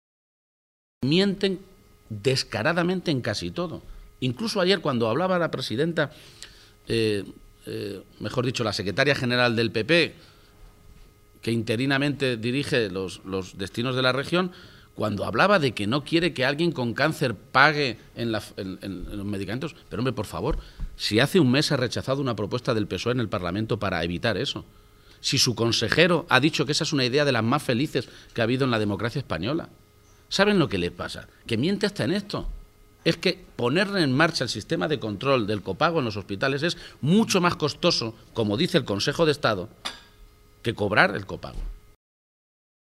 Emiliano García-Page durante el desayuno informativo celebrado en Cuenca